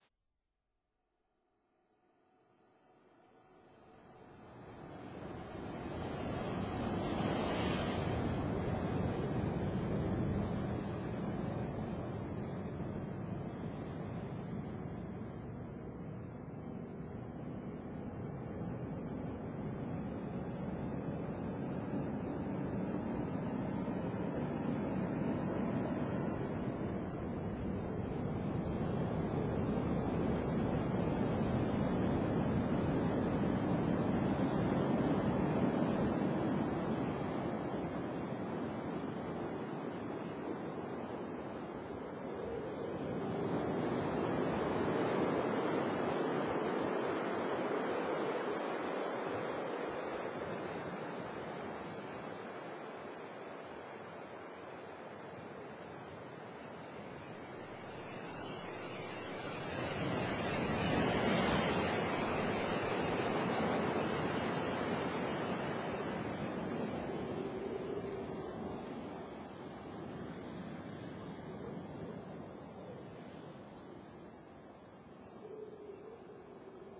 for tape